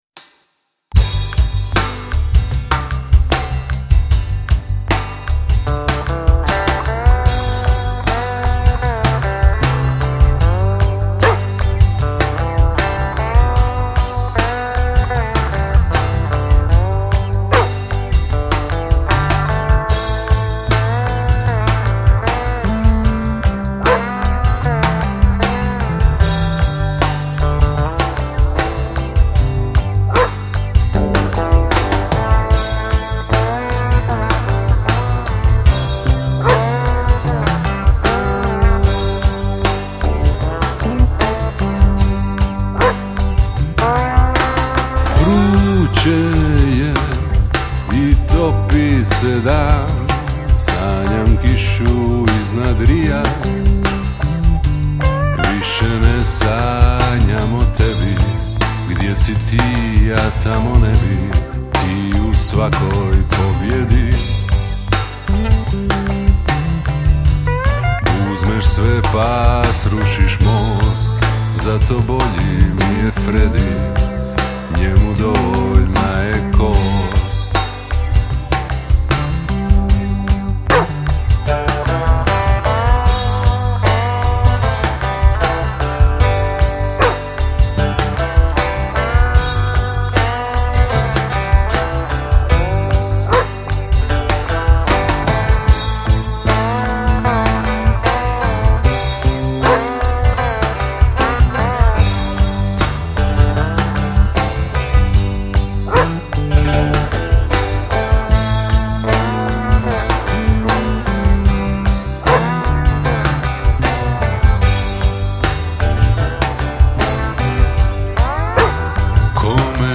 gitara, banjo, glas
saxovi
truba
trombon